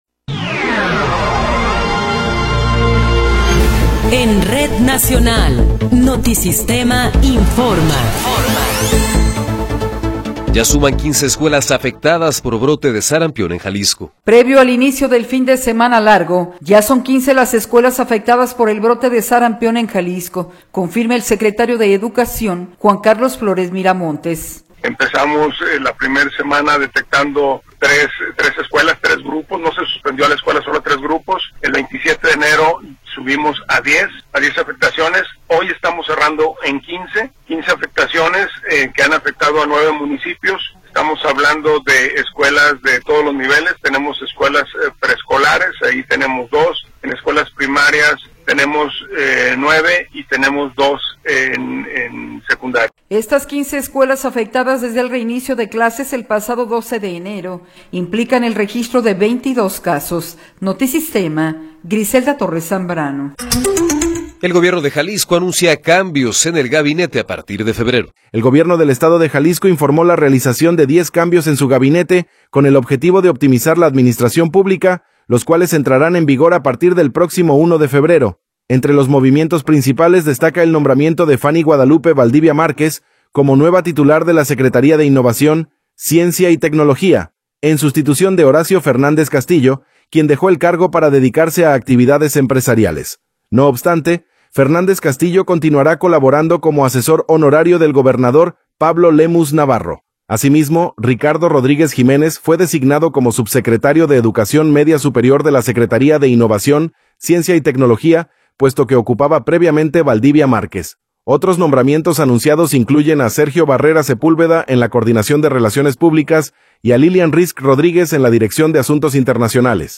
Noticiero 12 hrs. – 30 de Enero de 2026